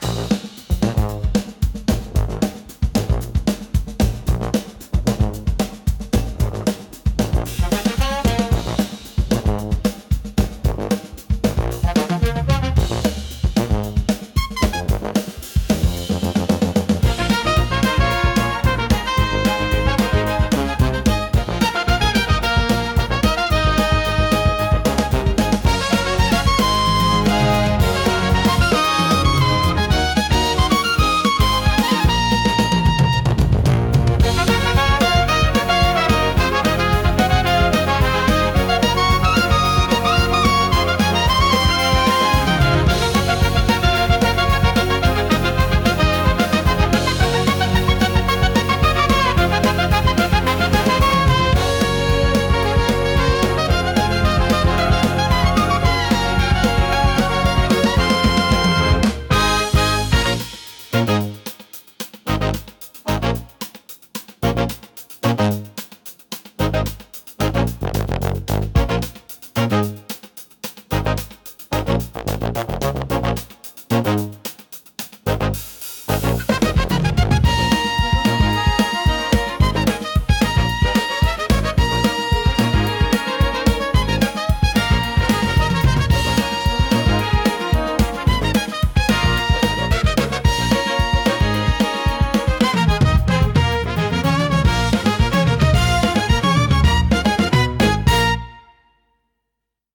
のブラスアレンジです。